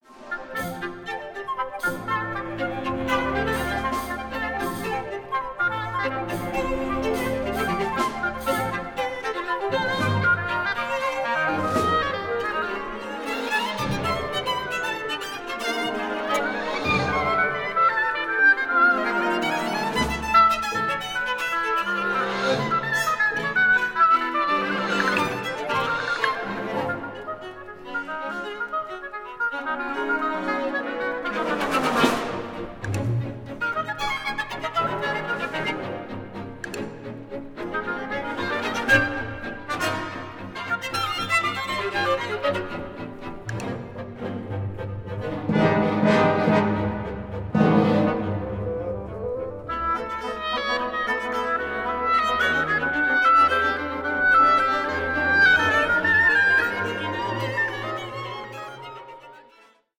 for Oboe, Violin and Orchestra
Allegro 4:28